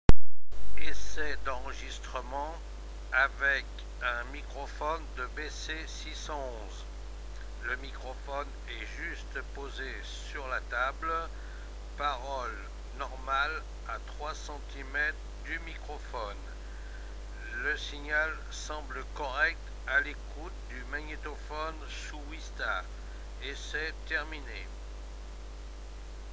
micro-test.mp3